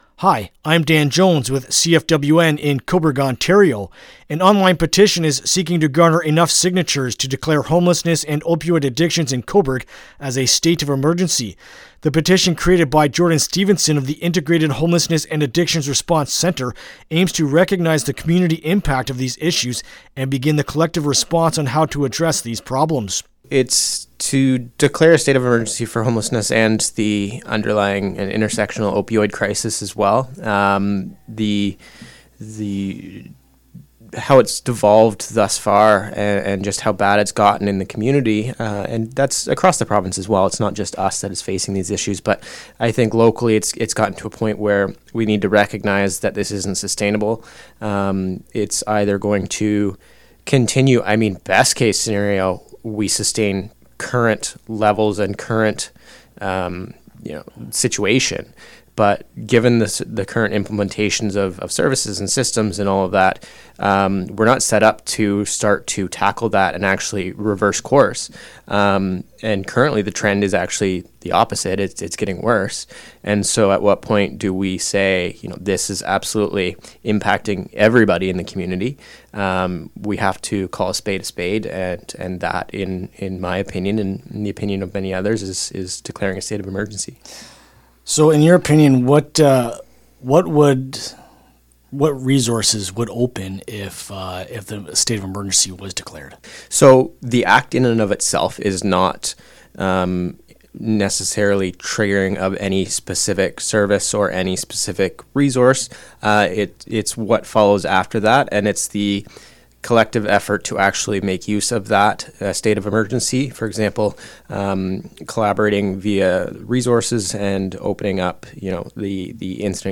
Homelessness-Petition-Interview-LJI.mp3